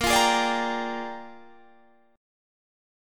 Bb13 Chord (page 3)
Listen to Bb13 strummed